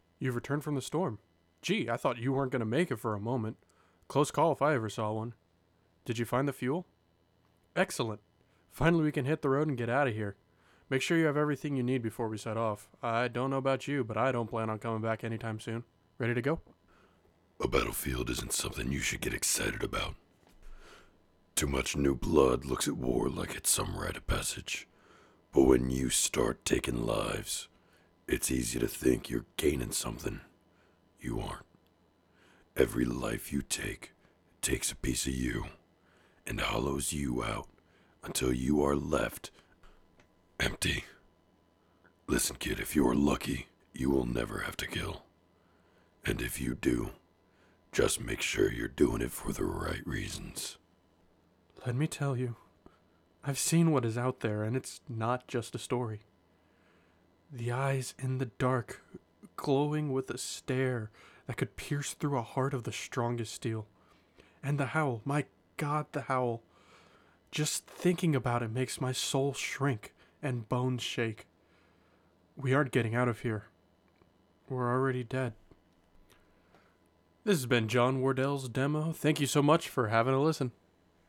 Video Game
English - USA and Canada
Young Adult
VideoGame_Demo.mp3